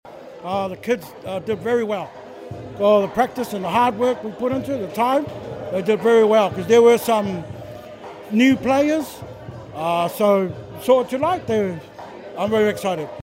It was a full house as people turned up to the official opening of the Niue Arts Festival 2025 in their colorful attires at the old Fale Fono Foyer on Tuesday night.